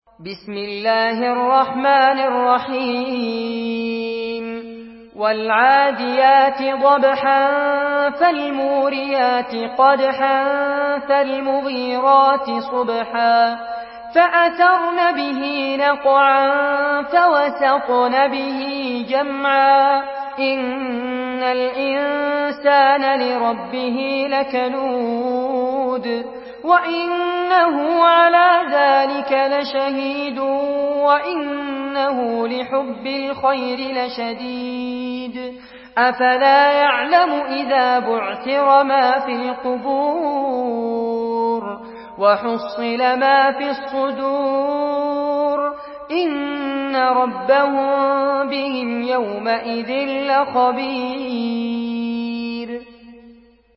سورة العاديات MP3 بصوت فارس عباد برواية حفص عن عاصم، استمع وحمّل التلاوة كاملة بصيغة MP3 عبر روابط مباشرة وسريعة على الجوال، مع إمكانية التحميل بجودات متعددة.
مرتل